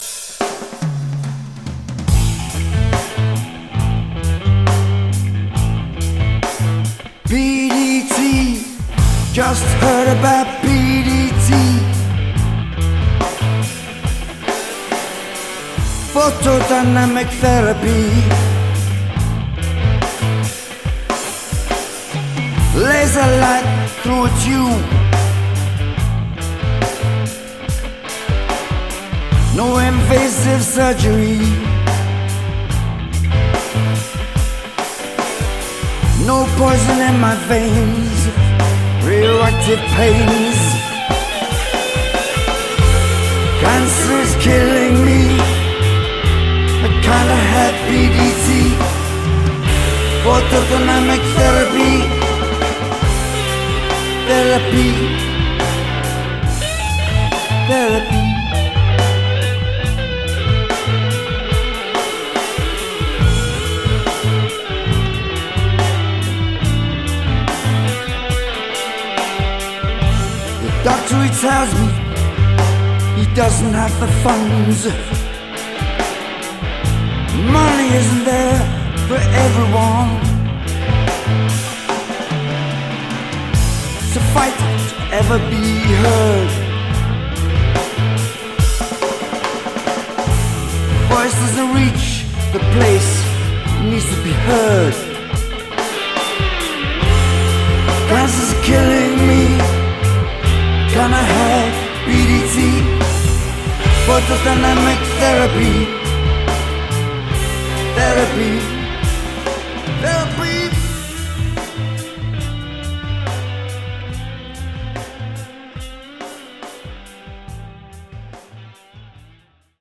lead guitarist